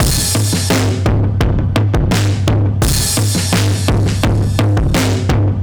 Index of /musicradar/analogue-circuit-samples/85bpm/Drums n Perc
AC_SlackDrumSB_85-04.wav